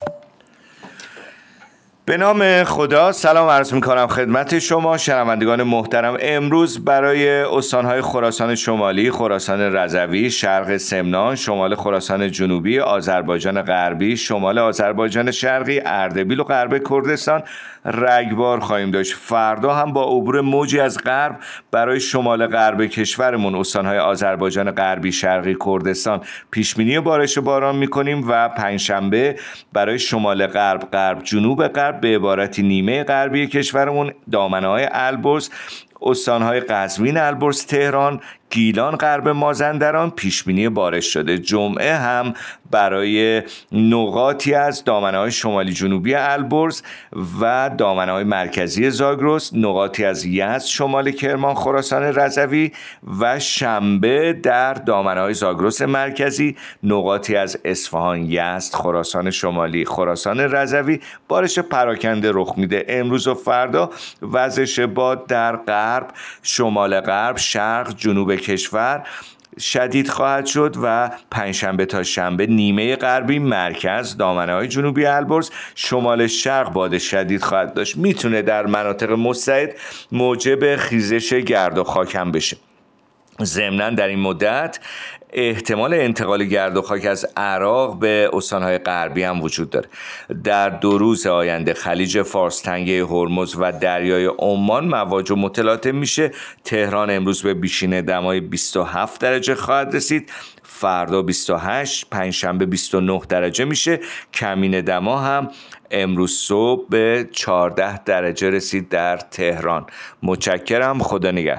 گزارش رادیو اینترنتی پایگاه‌ خبری از آخرین وضعیت آب‌وهوای ۱۹ فروردین؛